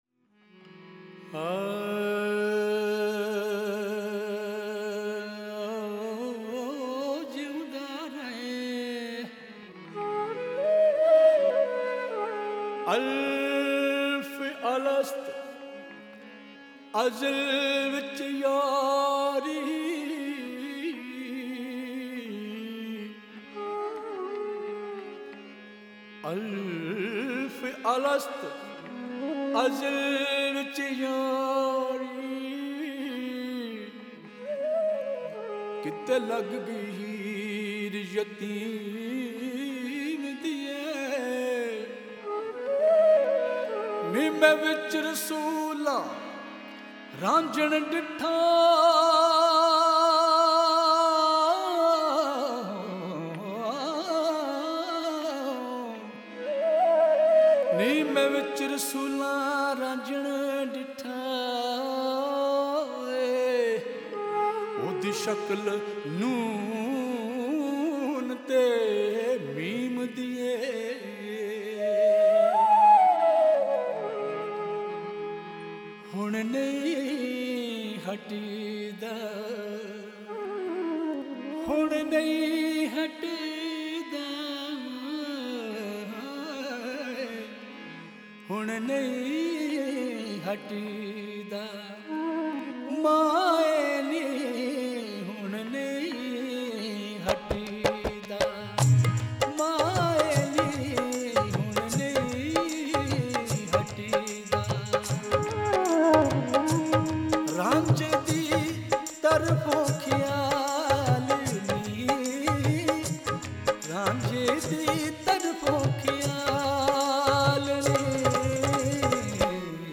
Punjabi Kalam